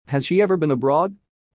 このままの速度でお聞きください。
【ノーマル・スピード】